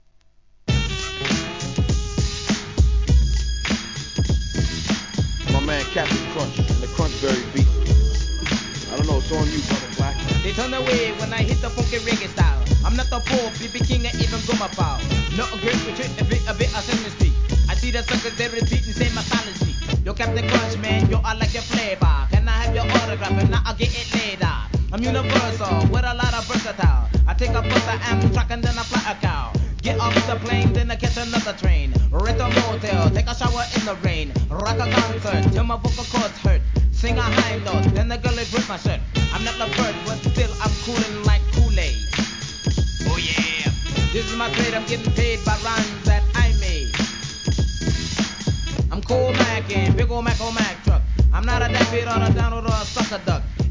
G-RAP/WEST COAST/SOUTH
FUNKYなラガHIP HOP CLASSIC!!